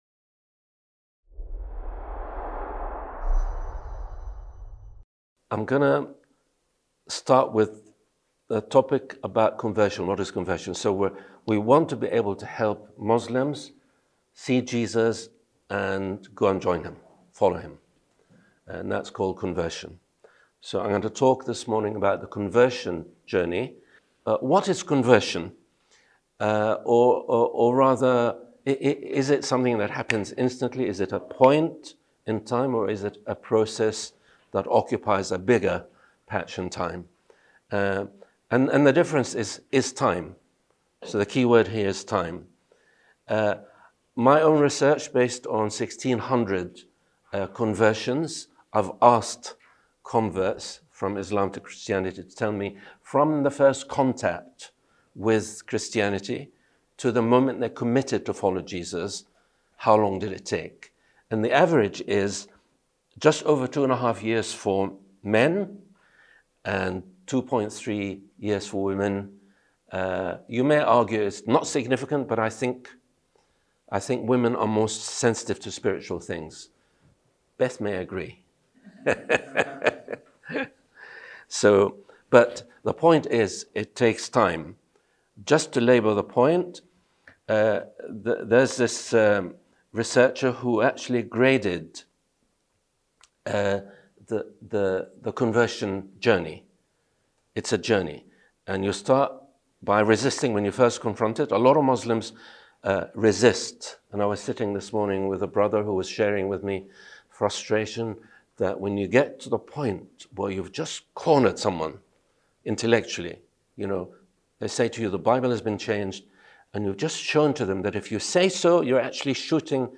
In this talk, we explore what Muslim Background Christians teach us about this journey, and how it informs our approach to evangelism.
Event: ELF Muslim Ministry Network